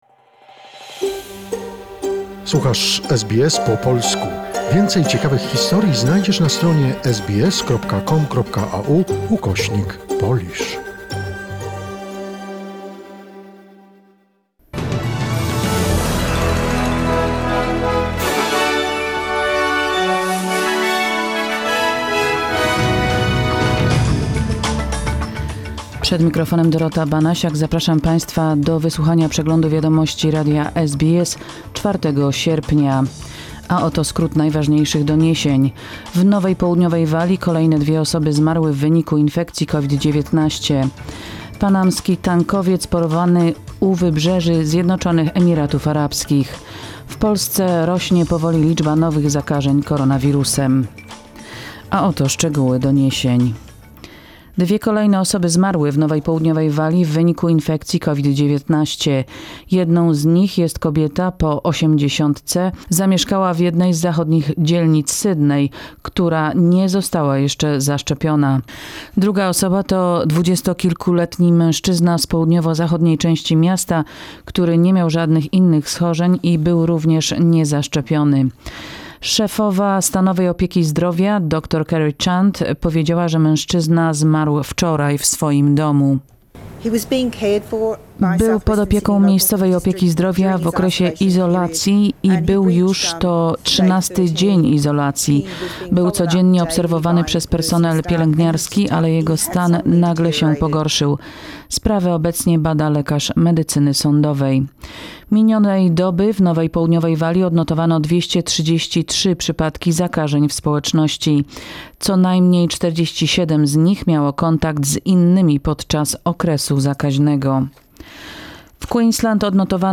SBS News in Polish, 4 August 2021